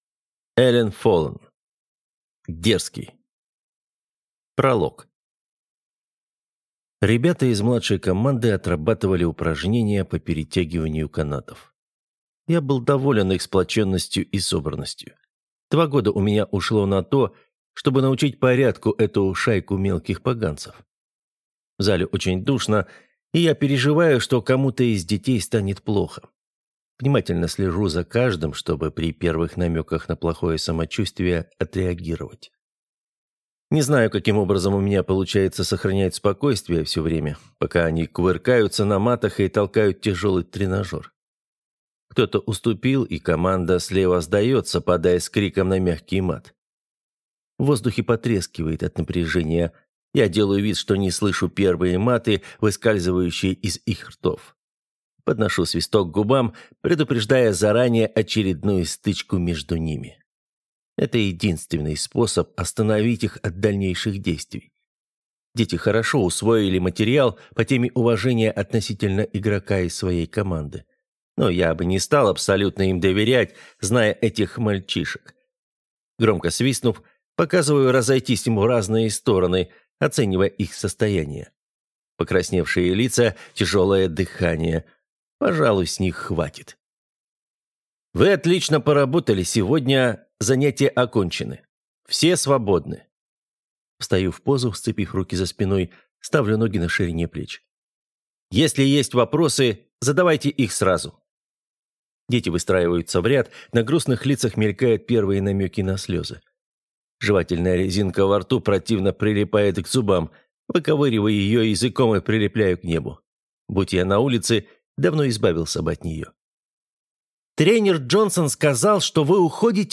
Аудиокнига Дерзкий - купить, скачать и слушать онлайн | КнигоПоиск